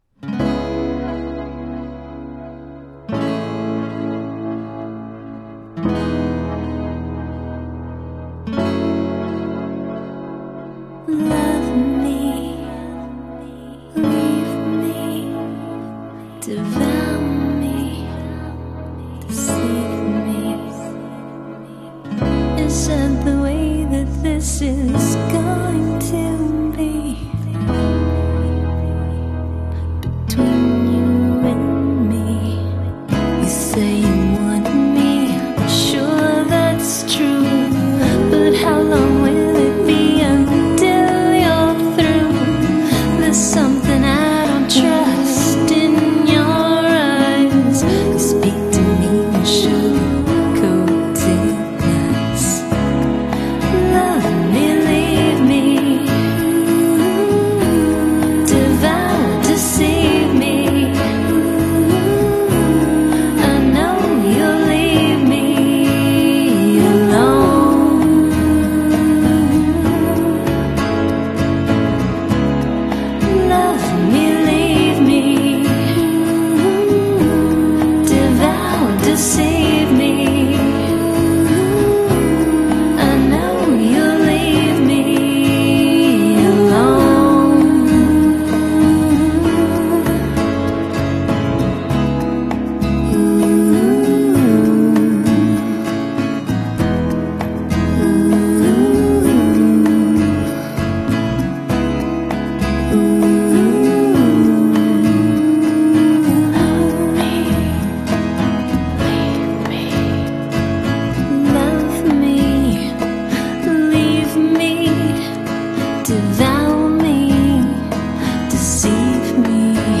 wistful acoustic guitar
and groovy congas
a soulfully ethereal backing vocal to the verses
a dreamy Baeleric-infused chill out mix